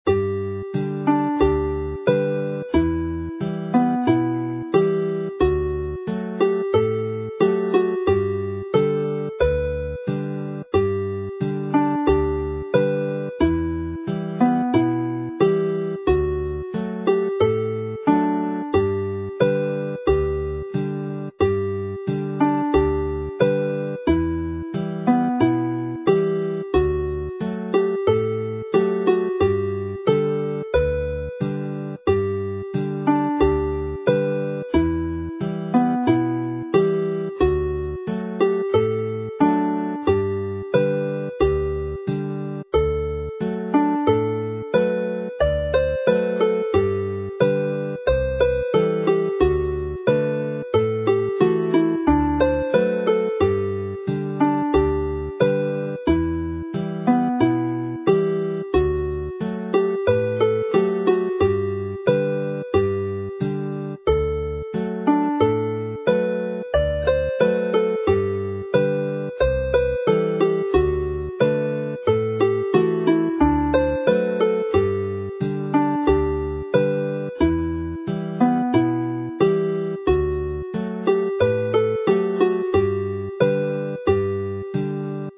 Uchder Cader Idris (in G)
Play slowly